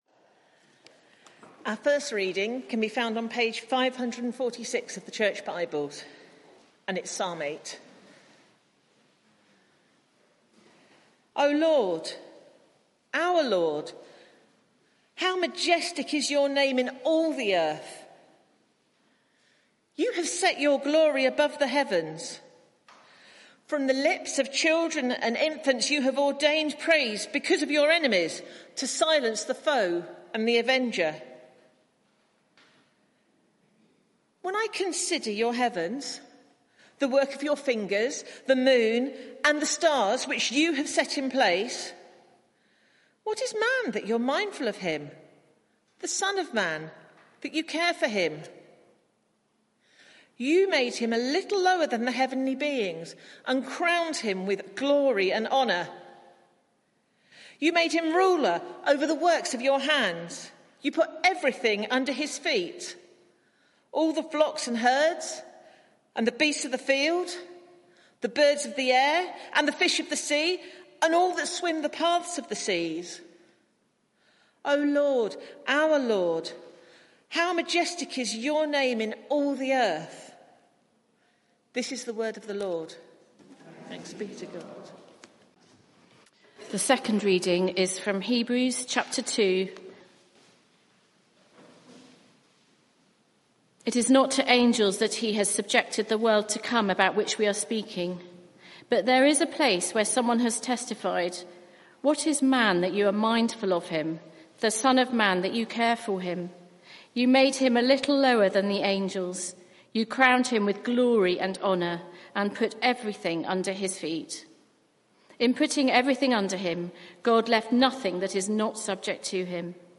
Media for 11am Service on Sun 30th Jul 2023 11:00 Speaker
Sermon (audio)